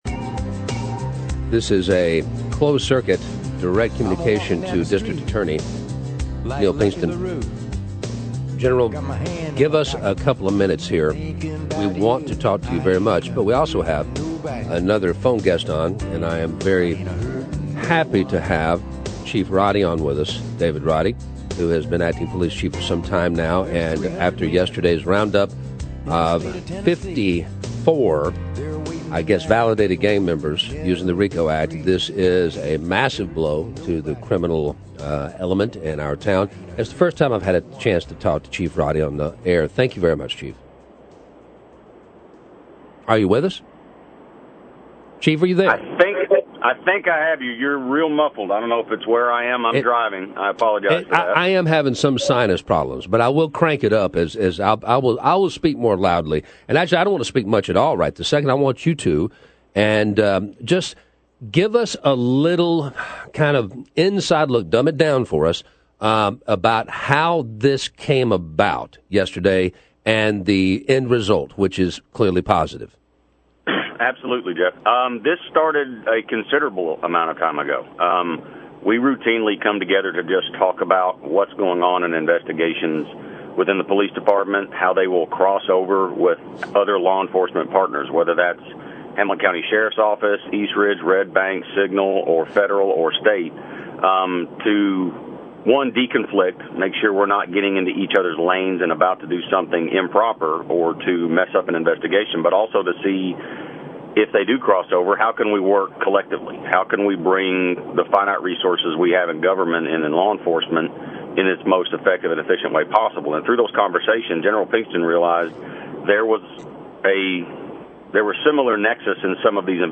Chattanooga Police Chief David Roddy and Hamilton County DA Neil Pinkston talk to the Morning Press crew about the 54 indictments of gang members in the city.